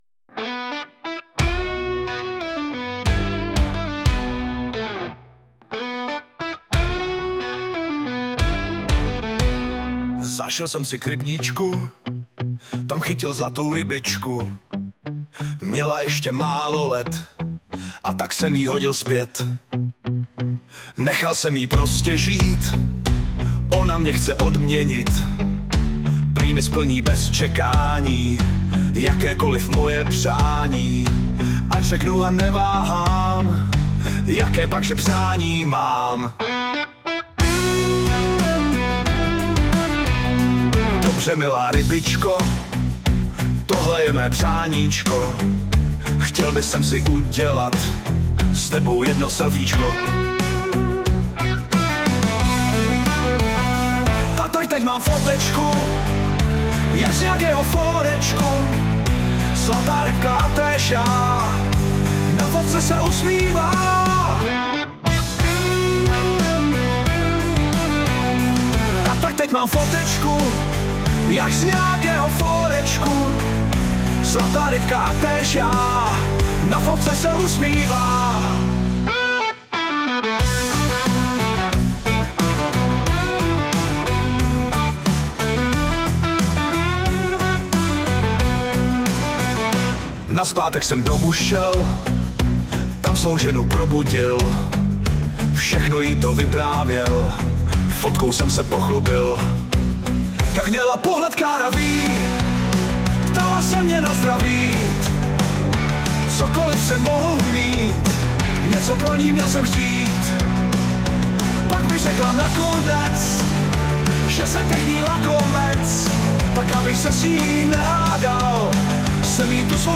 Anotace: Zhudebněno pomoci AI.